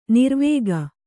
♪ nirvēga